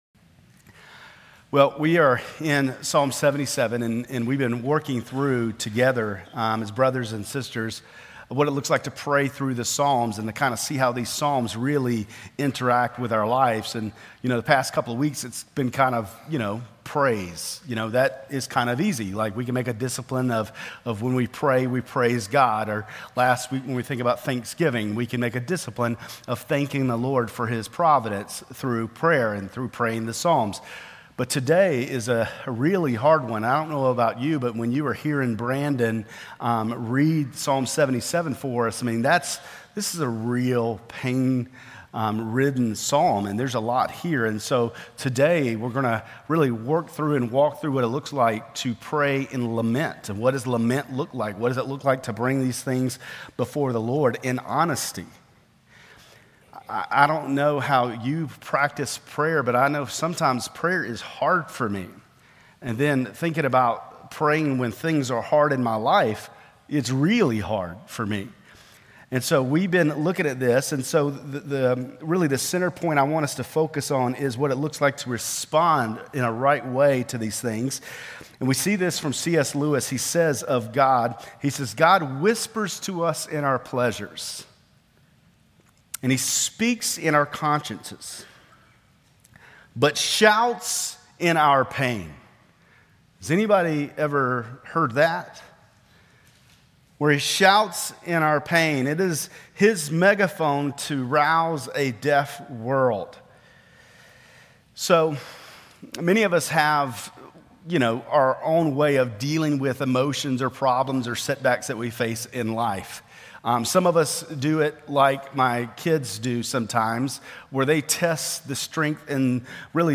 Grace Community Church Lindale Campus Sermons Psalm 77 - Lament Jul 07 2024 | 00:28:51 1x 00:00 / 00:28:51 Subscribe Share RSS Feed Share Link Embed